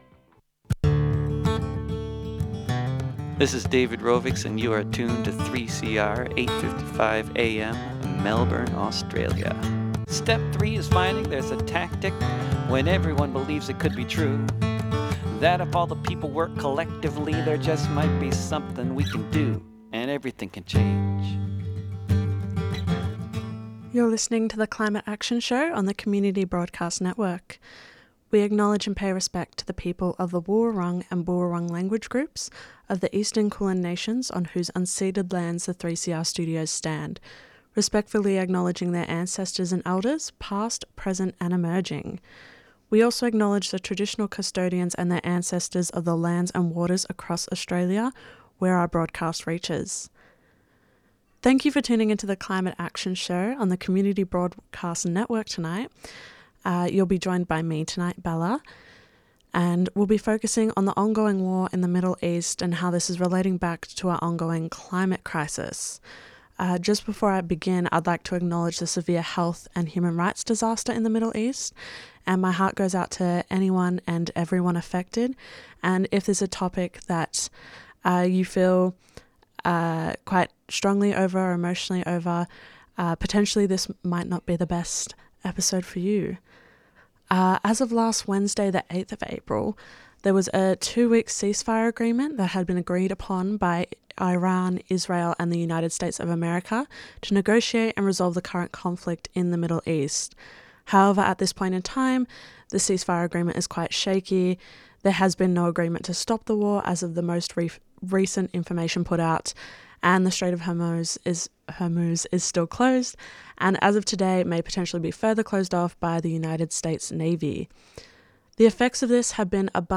To get a better understanding on Australia’s unreliable fossil fuel dependency, we’ll be hearing from the Doctors for the Environment Australia’s Afterhours Webinar: Quitting Our Oil Dependency discussing the effects the fuel crises is having in agricultural, medical and renewable energy sectors.